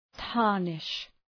Προφορά
{‘tɑ:rnıʃ}